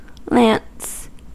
Ääntäminen
IPA : /lɑːns/ IPA : /læns/